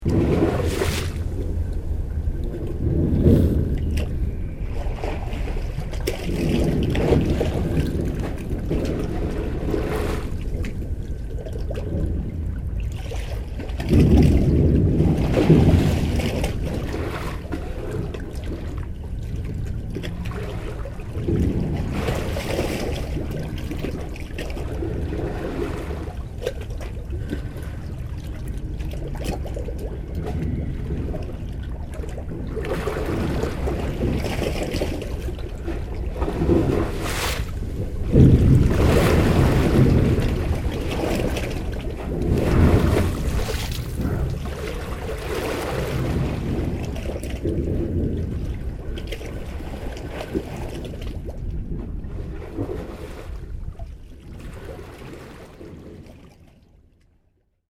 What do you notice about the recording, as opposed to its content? Schiehaven, july 09 For the recording I used a directional microphone, a contact microphone, an ultrasonic detector (bats) and a binaural microphone.